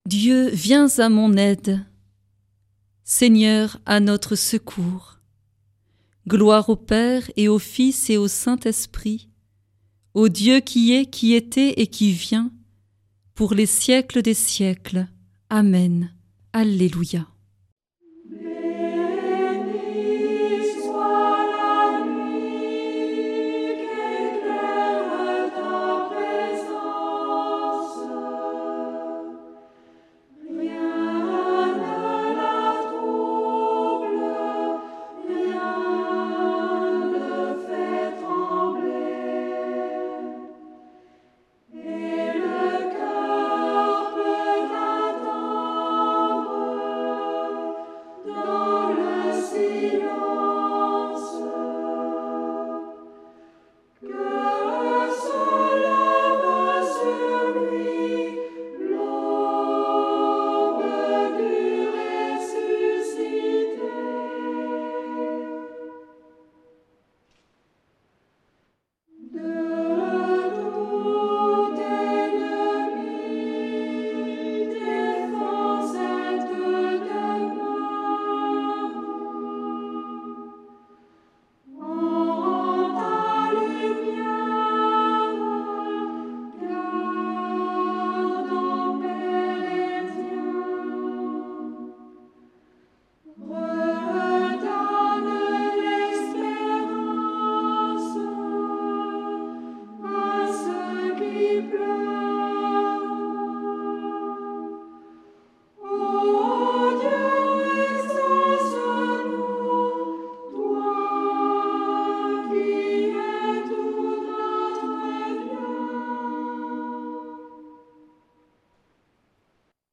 Prière des complies - Temps ordinaire
Une émission présentée par Groupes de prière